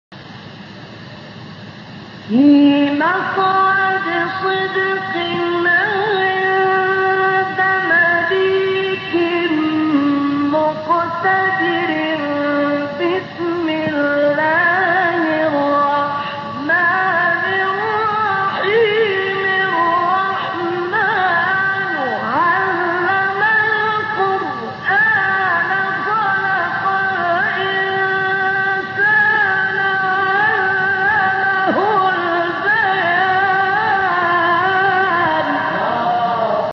فرازهای صوتی از قاریان به‌نام مصری